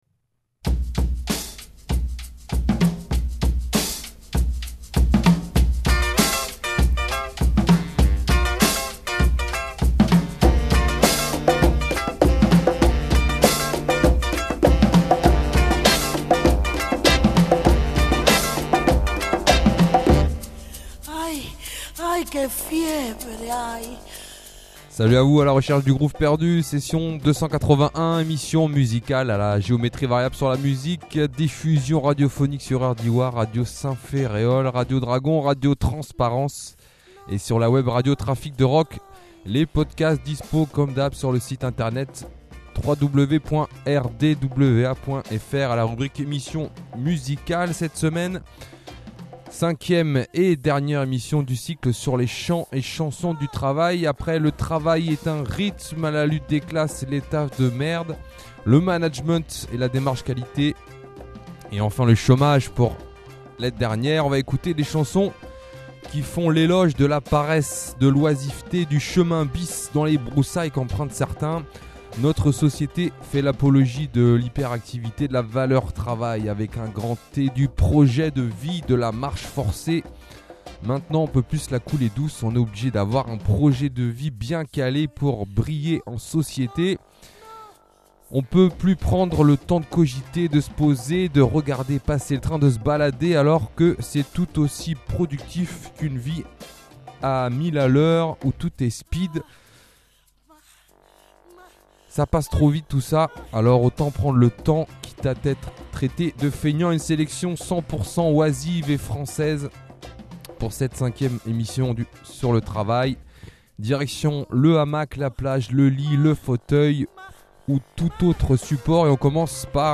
Pour finir la boucle, on va écouter des chansons qui font l’éloge de la paresse, de l’oisiveté, du chemin bis dans les broussailles qu’empreuntent certains. Selection 100% chanson francaise ou francophone.